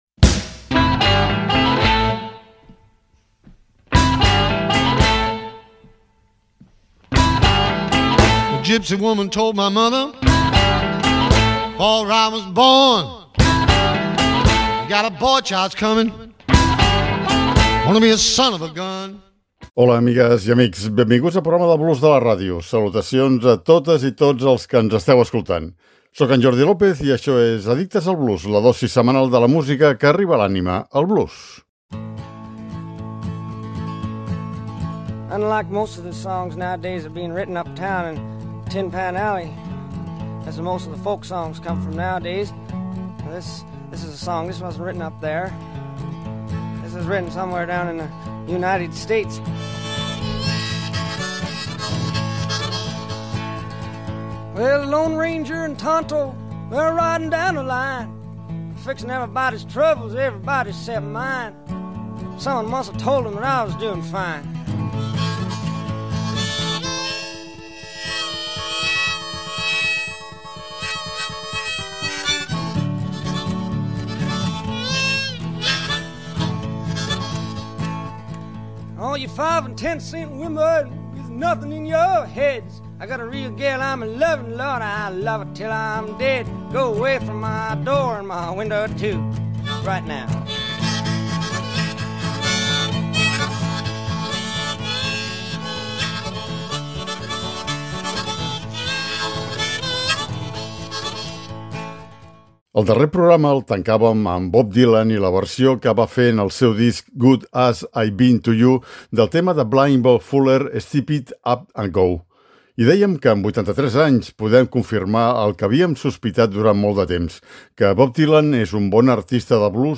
sis cançons tradicionals de blues i sis composicions pròpies